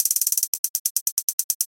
简单的帽子 1
Tag: 140 bpm Trap Loops Percussion Loops 295.49 KB wav Key : Unknown